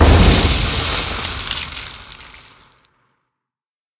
僵尸村民：撕心裂肺